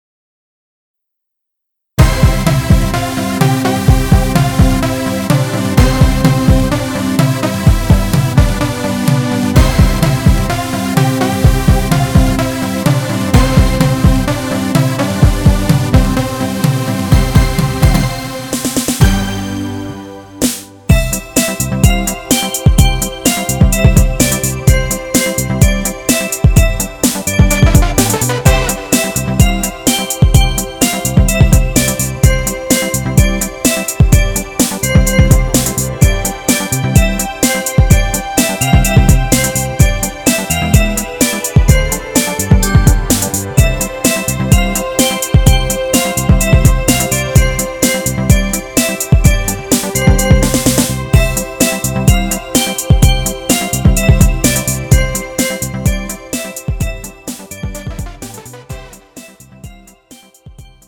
음정 C 키
Pro MR은 공연, 축가, 전문 커버 등에 적합한 고음질 반주입니다.